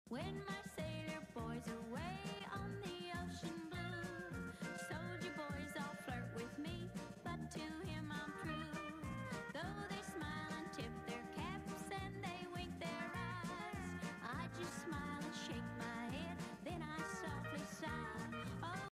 CountryMusic